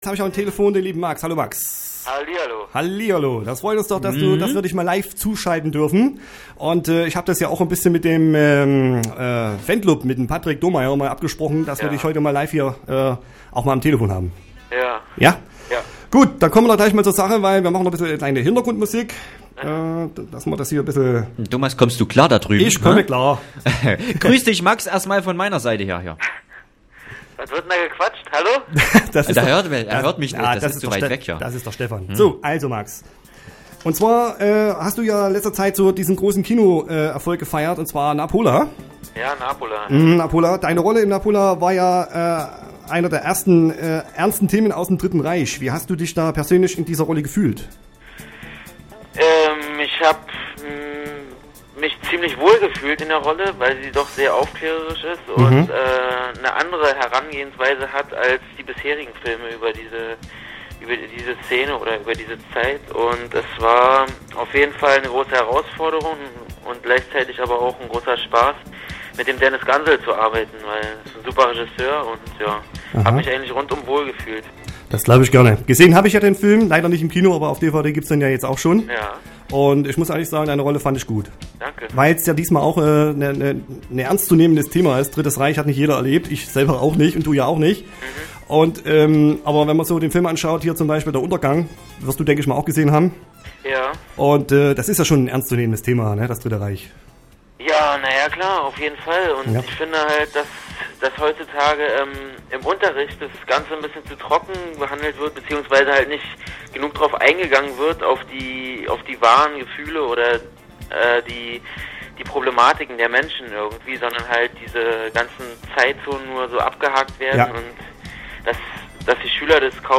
Interview Web-Radio Plauen – Max Riemelt